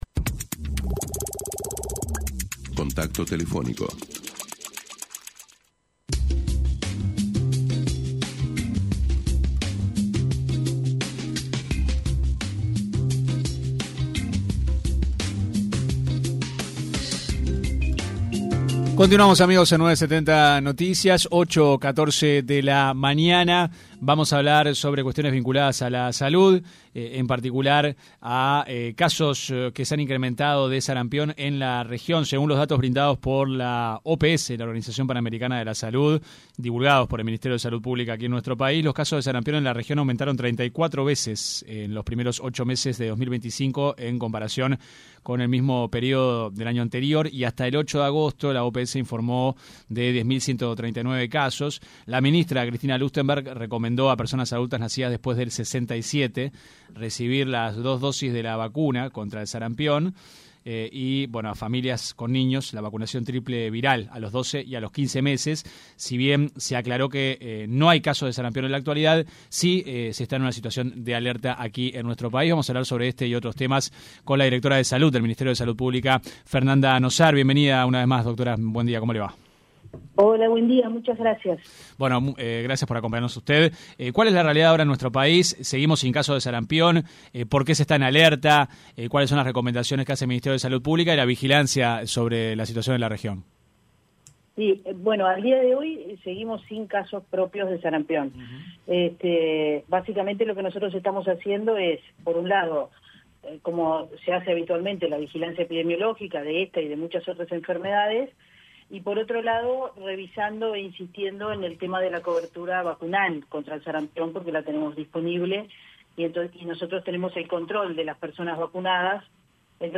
La directora general de Salud, del Ministerio de Salud Pública, Fernanda Nozar, se refirió en una entrevista con 970 Noticias al tema de la salud mental y cómo lo están abordando desde la cartera.